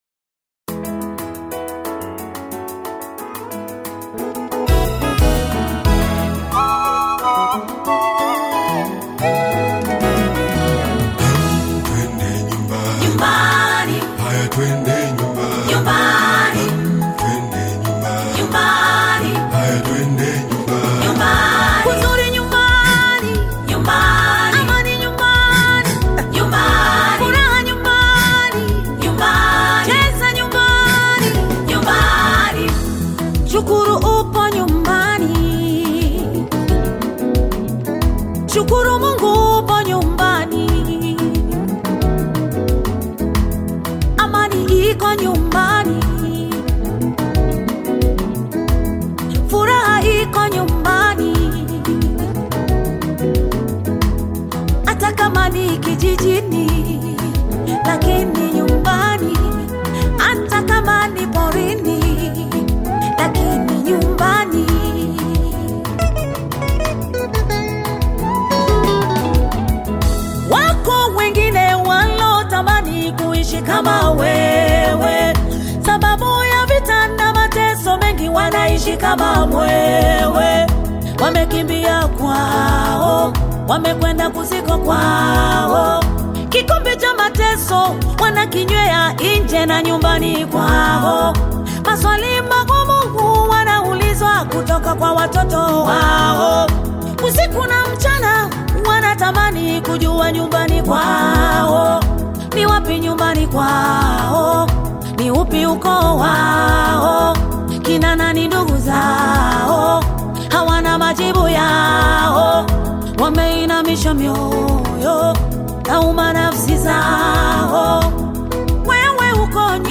Gospel music track
Tanzanian gospel artist, singer, and songwriter
Gospel song